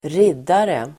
Ladda ner uttalet
Uttal: [²r'id:are]